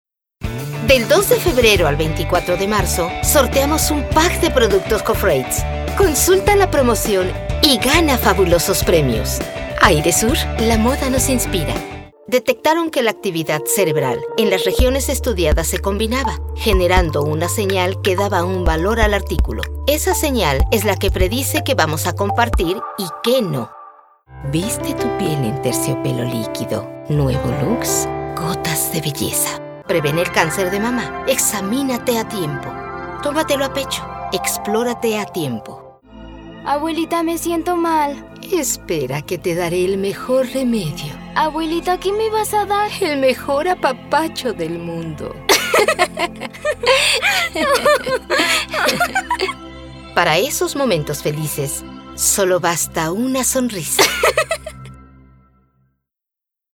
西班牙语配音
西班牙语女声